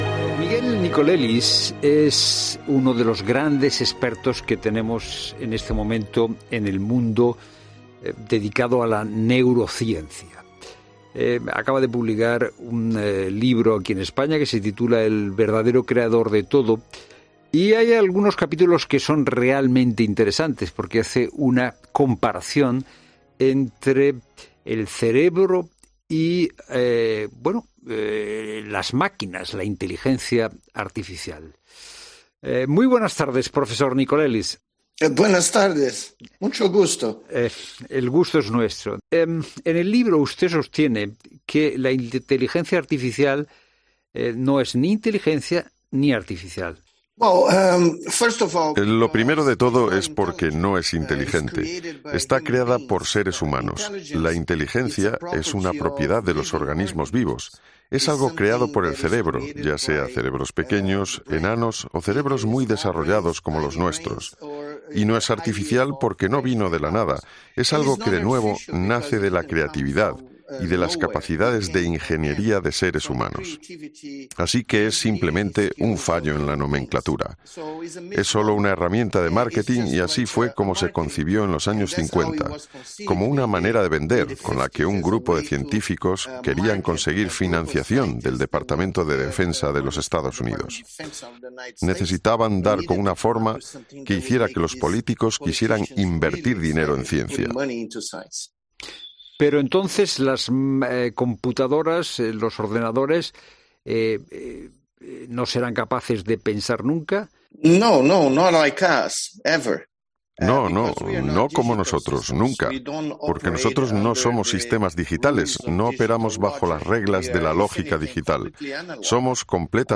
Miguel Nicolelis es experto en neurociencia y ha explicado en 'La Tarde' de COPE que la IA no es ni inteligencia ni artificial